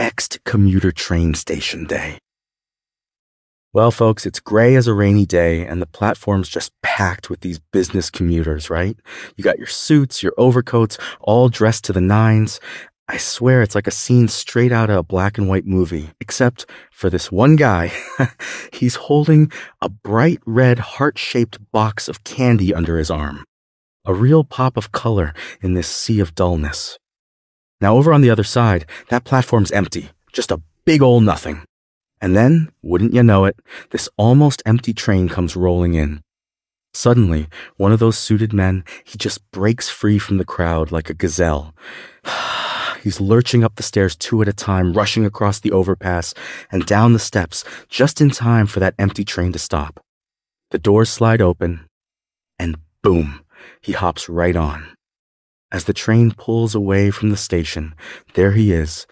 Windows Latest tested Copilot Audio Expressions, an AI voice generation tool that can produce a human-like audio output for your script and even take creative control if you wish it that way.
We chose Emote as the mode option with the Oak voice and narration style.
Surprisingly, the audio clip took the liberty with the supplied script and added bits, rephrased sentences to sound more engaging.